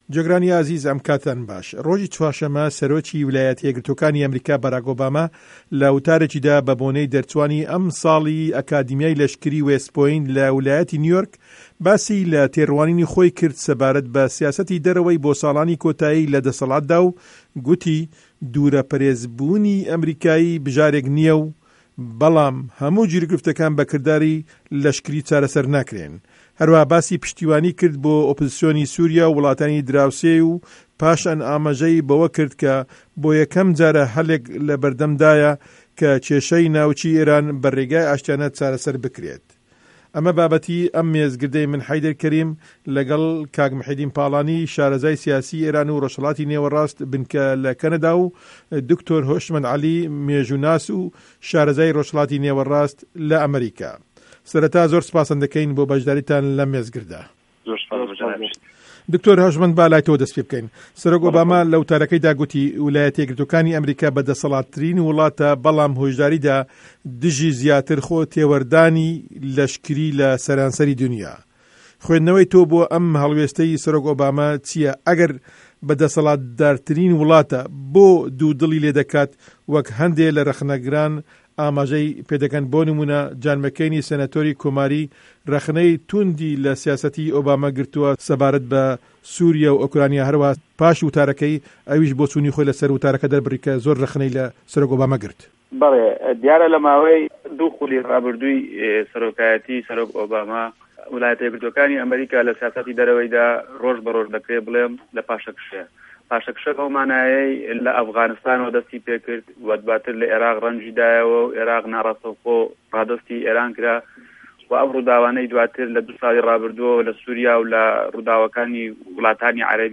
مێزگردێـک له‌ باره‌ی وتاری سه‌رۆک ئۆباما که‌ تایبه‌ت بوو به‌ سیاسه‌تی ده‌ره‌وه‌ی ئه‌مه‌ریکا